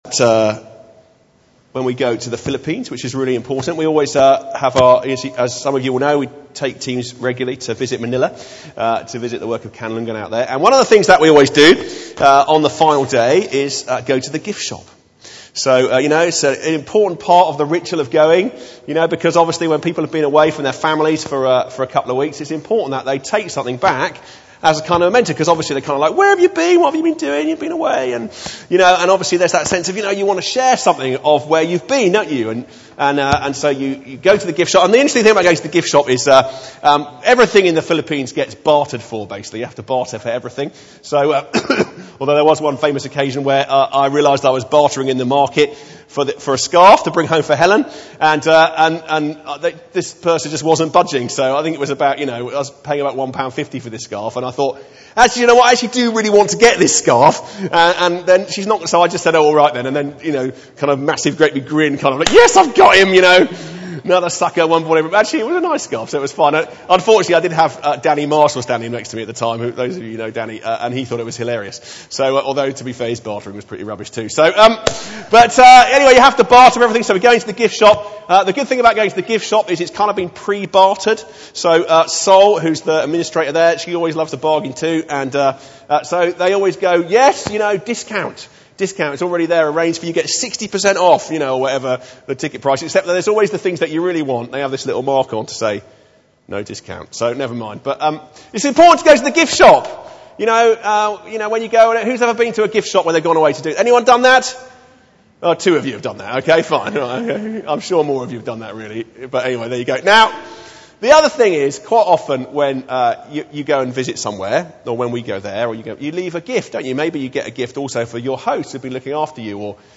in 10:30 Morning Worship, St John's service